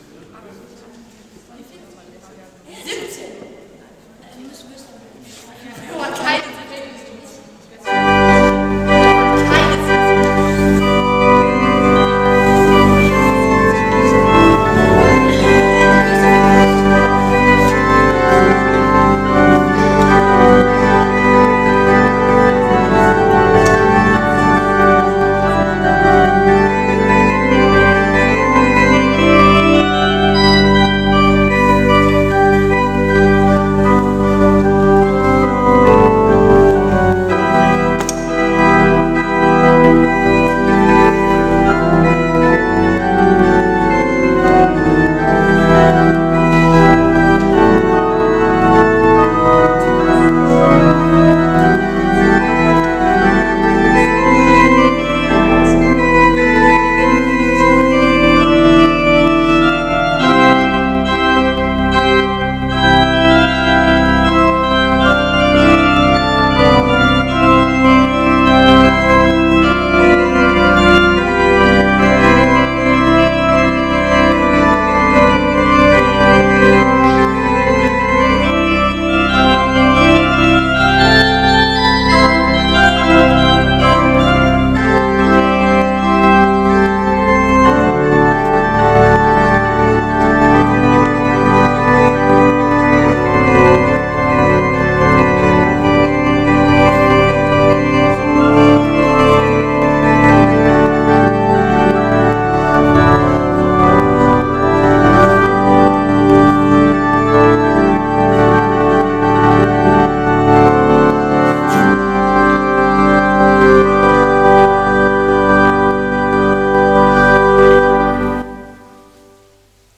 Aktuelle Predigt
Gottesdienst vom 25.01.2026 als Audio-Podcast Liebe Gemeinde, herzliche Einladung zum Gottesdienst vom 25. Januar 2026 in der Martinskirche Nierstein als Audio-Podcast.